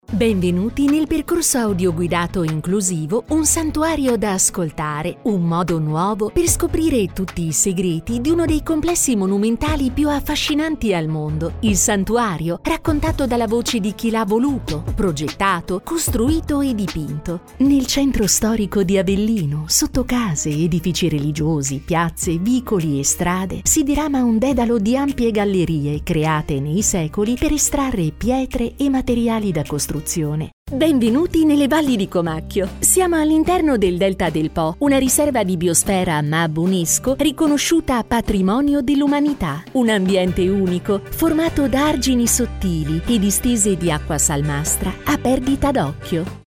Her articulate, engaging delivery suits commercials, narration, e-learning, and character work – perfect for brands seeking a professional Italian voice actor.
Audio Guides
Mic Neumann TLM103